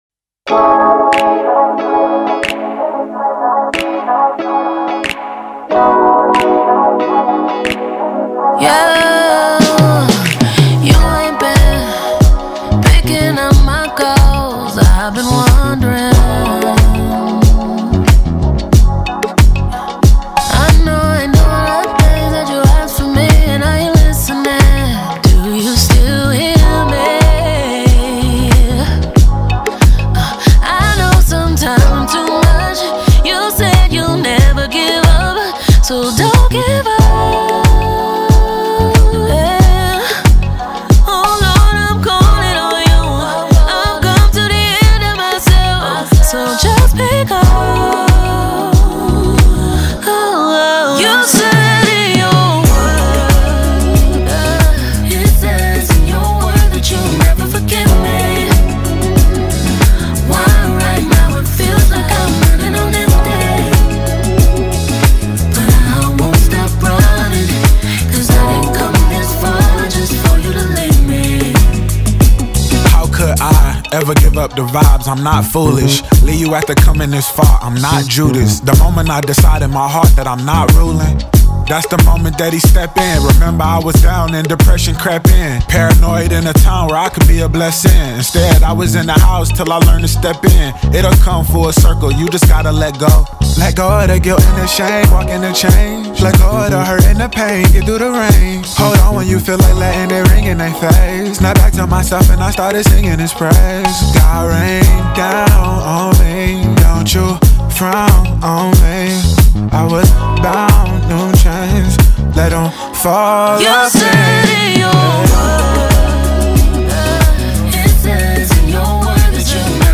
Christian Hip Hop